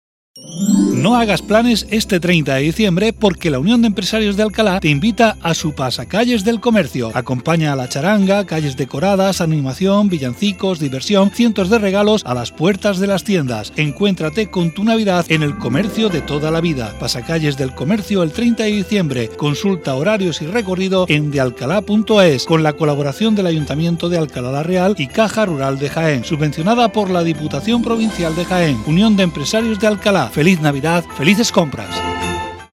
Spot publicitario pasacalles en radio Onda Cero Alcalá Sierra Sur durante toda la Navidad